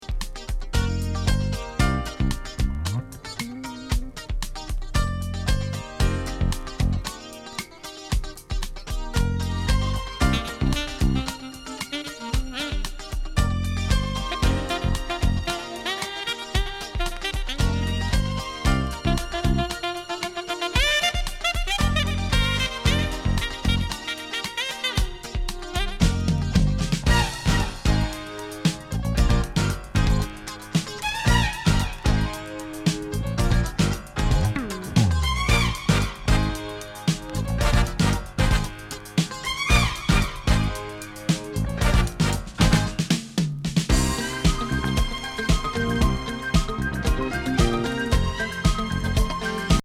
何気にキラーなスムース・インスト・ディスコ